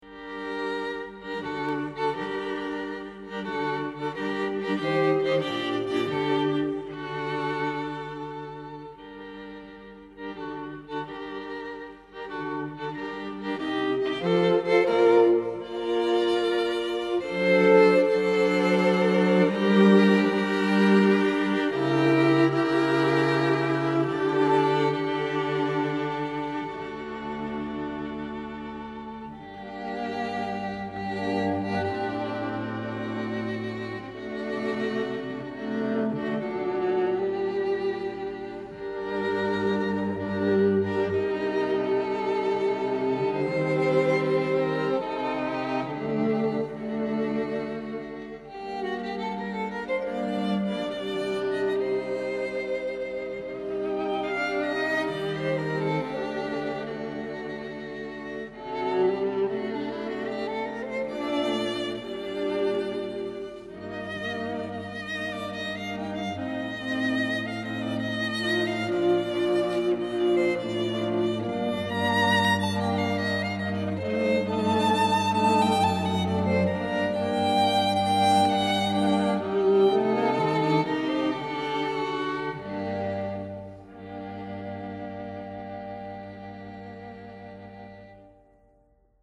A destacar la refinada y extremadamente sensitiva partitura